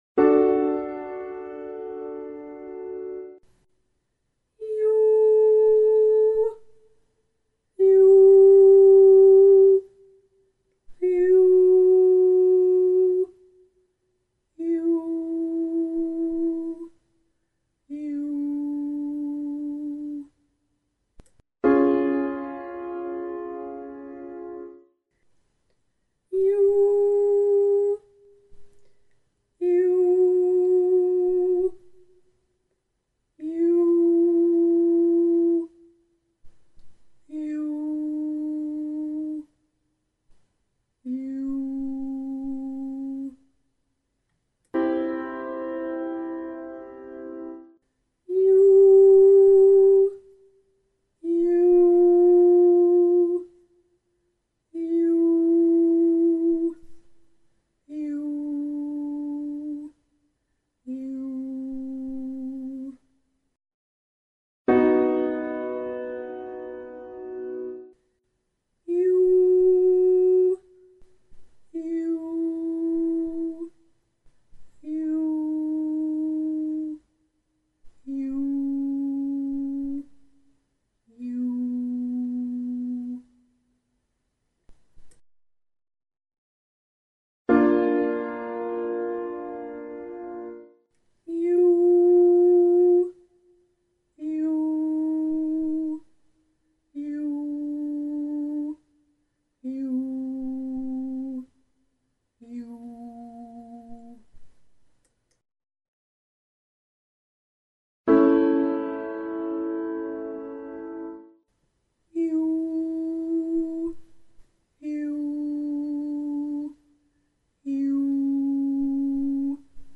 Staccato Triads – Basic
27D Half Note Breathing on /yoo/